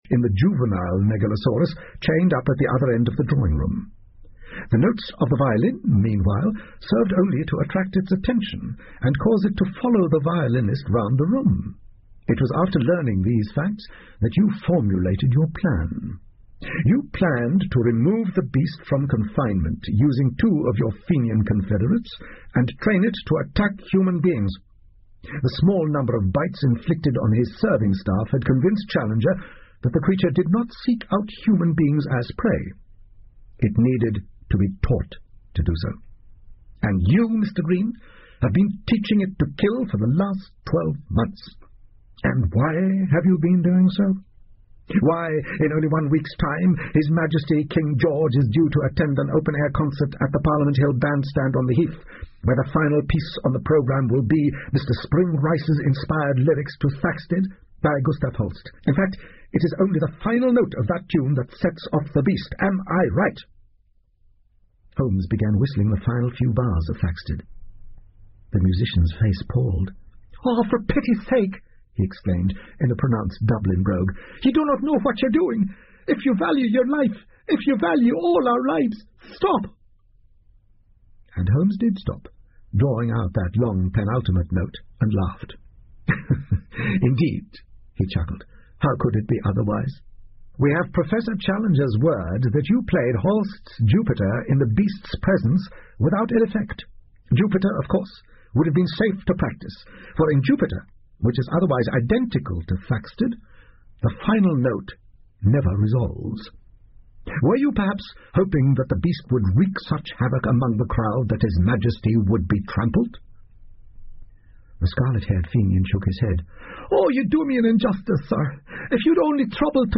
福尔摩斯广播剧 Cult-The Lost World 6 听力文件下载—在线英语听力室